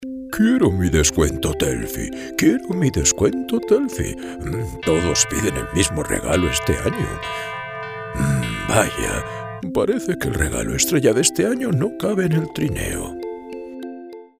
Cuña navideña de Papá Noel locutor de radio